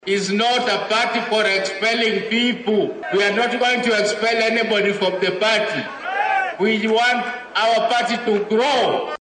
Hoggaamiyaha xisbiga ODM Oburu Oginga ayaa xaqiijiyay in xisbigu uusan eryi doonin hoggaamiyeyaasha xisbiga ee u muuqda inay ka soo horjeedaan go’aannada xisbiga. Isagoo ka hadlayay degmada Kisii, Oburu wuxuu sheegay inuu diyaar u yahay inuu wadahadal la yeesho xisbiga Uda si uu u sameeyo isbahaysi doorashada 2027.